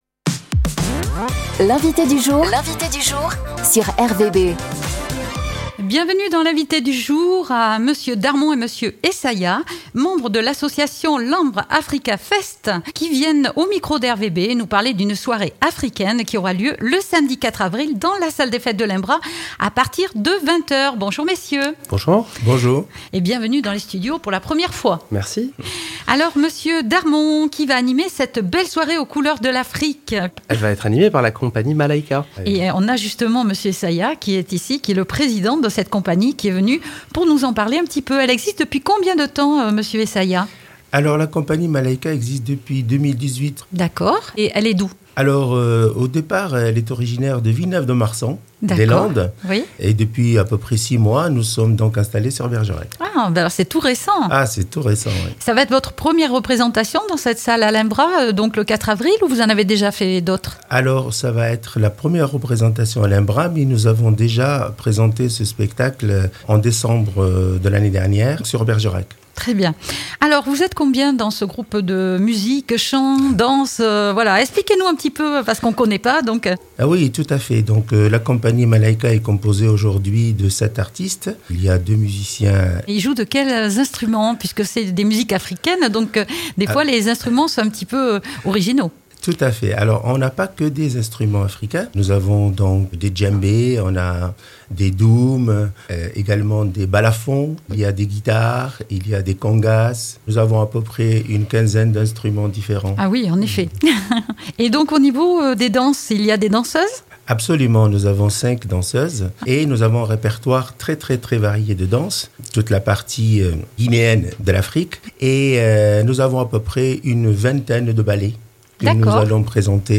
Au micro de RVB